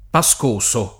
[ pa S k 1S o ]